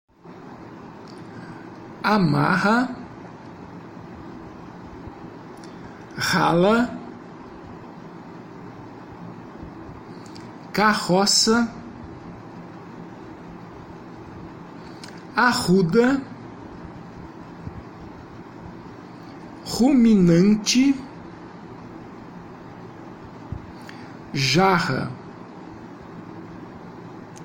Faça download dos arquivos de áudio e ouça a pronúncia das palavras a seguir para transcrevê-las foneticamente.
GRUPO 5 - Fricativa velar - Arquivo de áudio -->